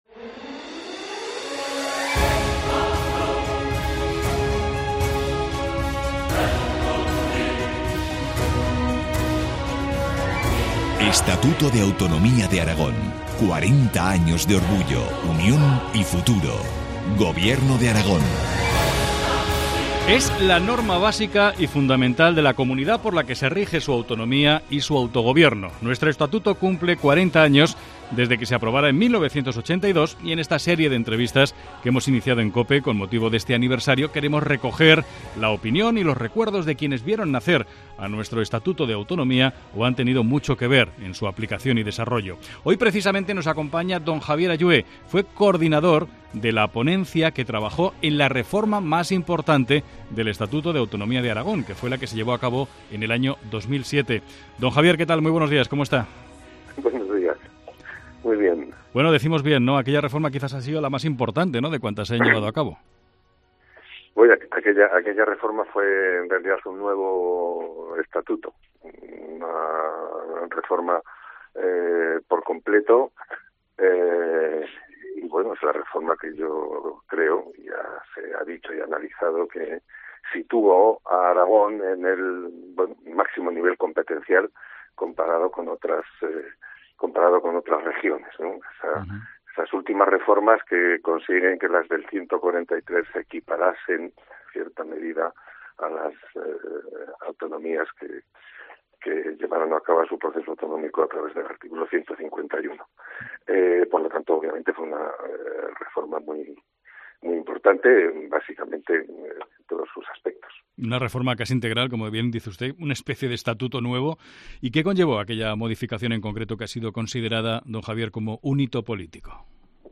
Entrevista a Javier Allué, coordinador de la ponencia que trabajó en 2007 en la reforma del Estatuto aragonés.